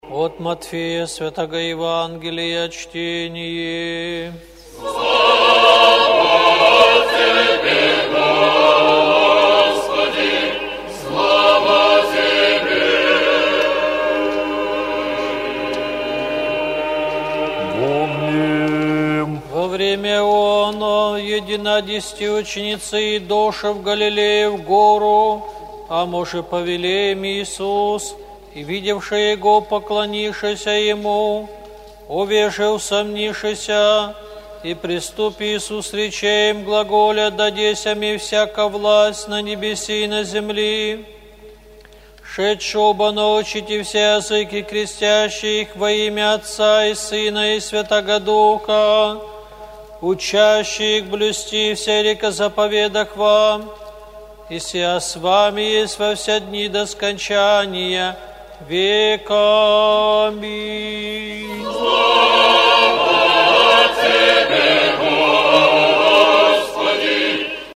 ЕВАНГЕЛЬСКОЕ ЧТЕНИЕ НА УТРЕНЕ